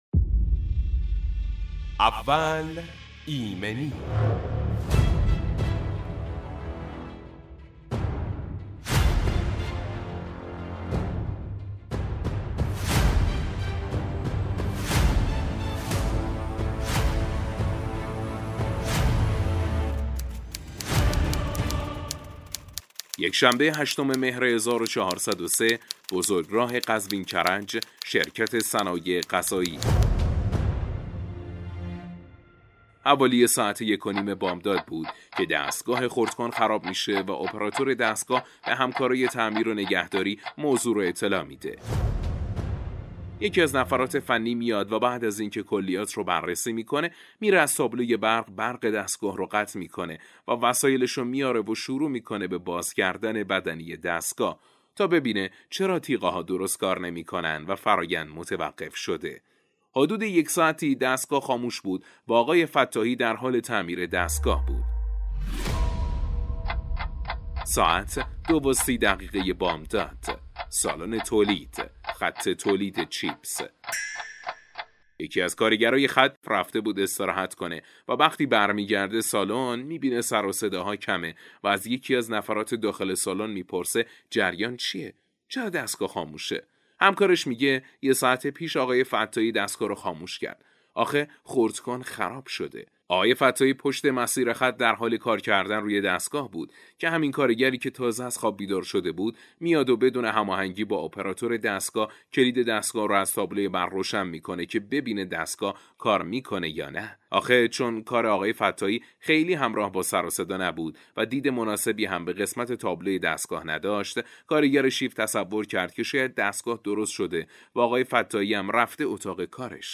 برنامه اول ایمنی به مدت ۱۵ دقیقه با حضور کارشناس متخصص آغاز و تجربیات مصداقی ایمنی صنعتی به صورت داستانی بیان می شود.